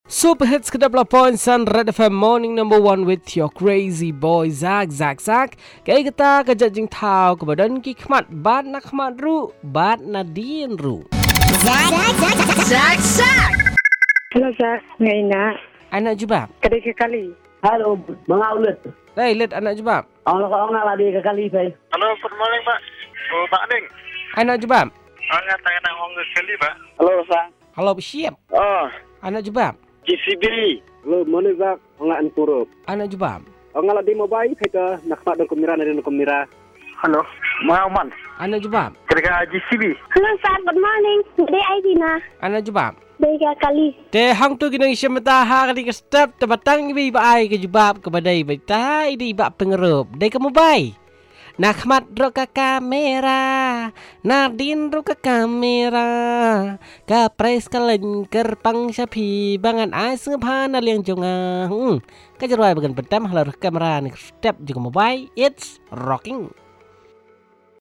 Calls and result